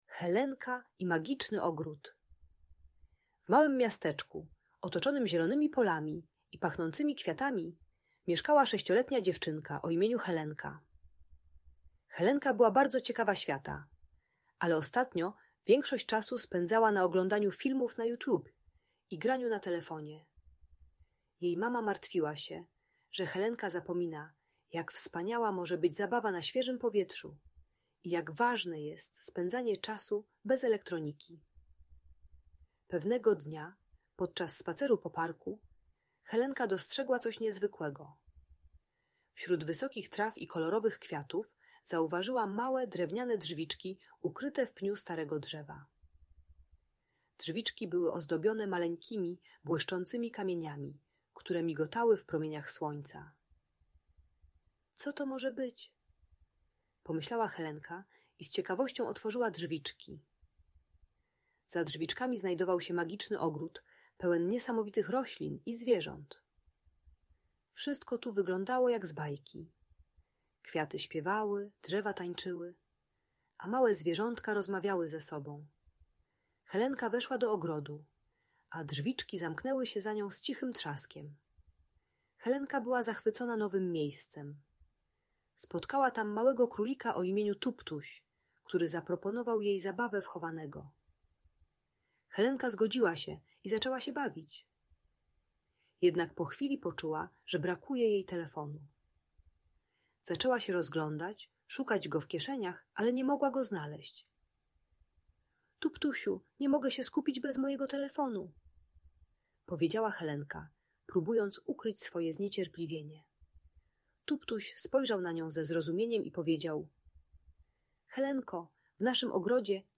Helenka i Magiczny Ogród - Audiobajka